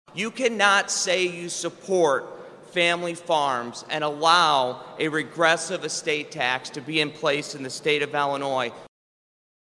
During Ag Day events this week, State Senator Andrew Chesney said the estate tax is wreaking havoc on family farms.